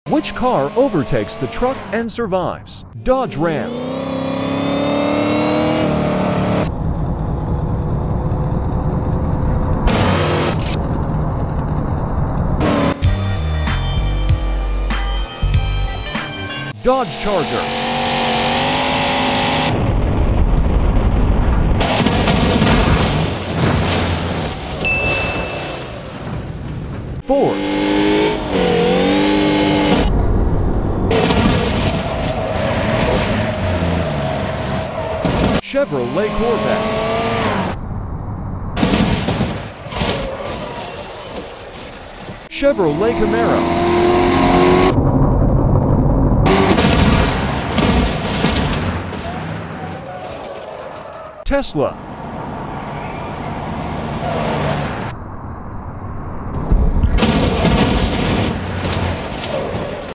Which car overtakes the truck sound effects free download